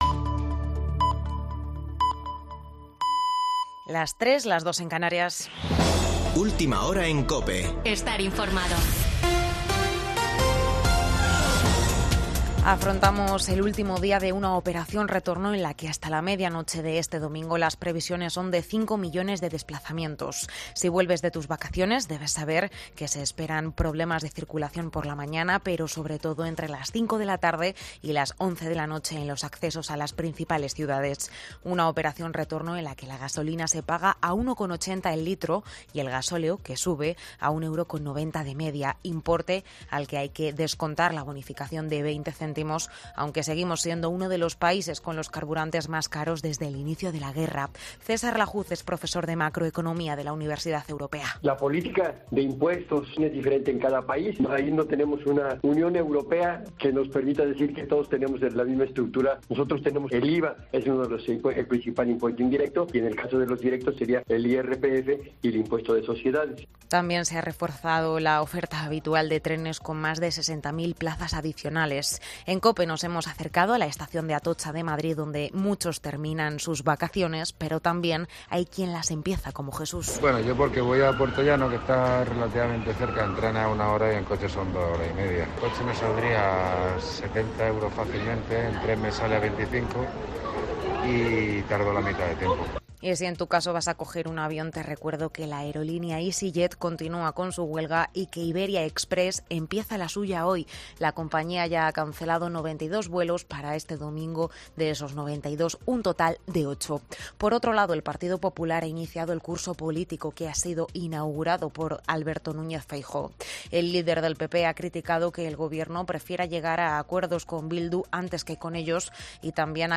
Boletín de noticias de COPE del 28 de agosto de 2022 a las 03.00 horas